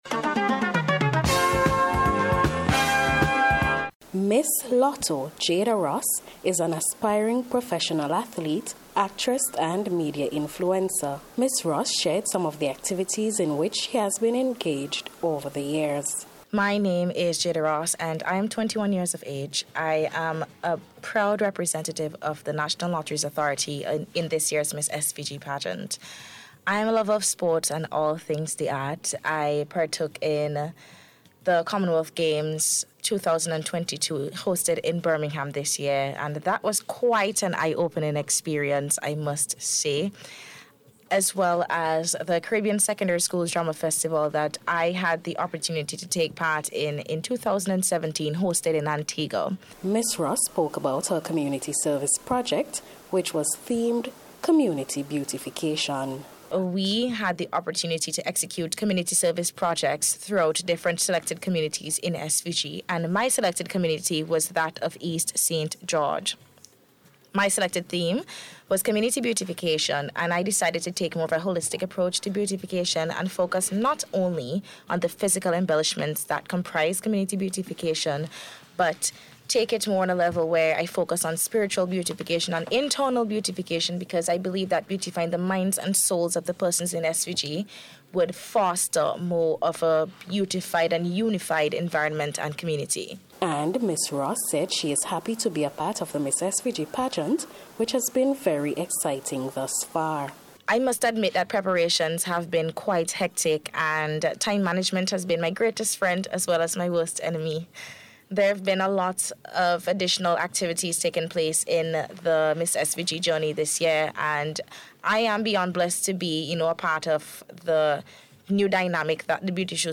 NBC’s Special Report – Wednesday October 19th 2022